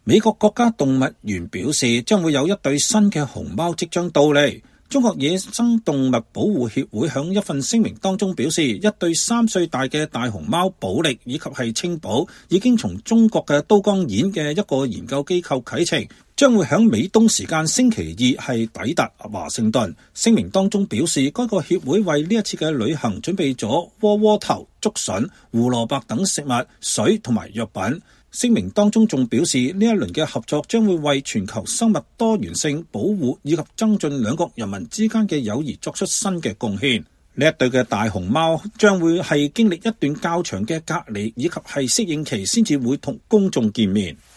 華盛頓國家動物園的大貓熊正在吃竹子。